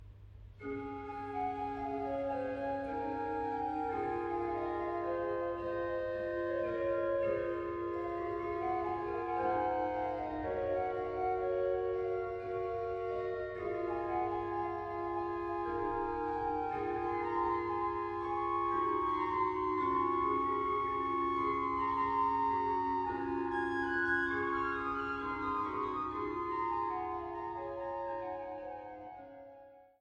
Orgel